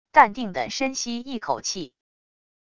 淡定的深吸一口气wav音频